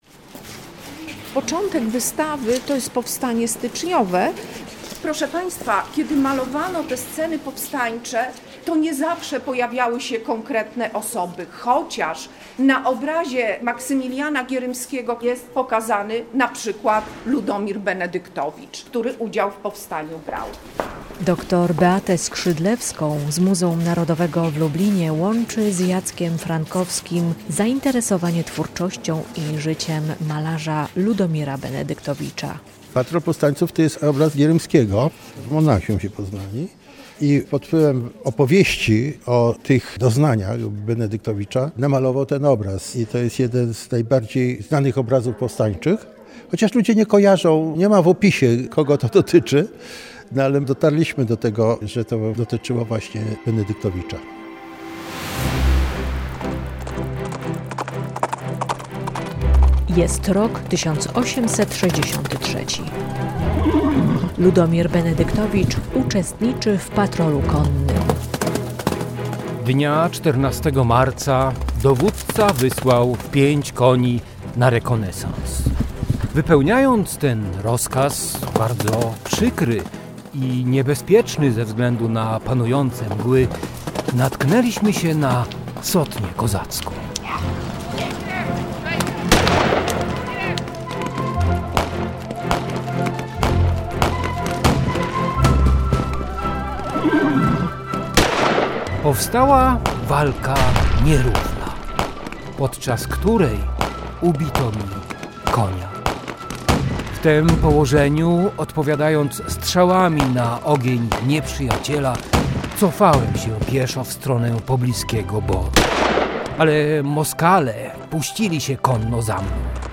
Dusza Tułacza Tagi: reportaż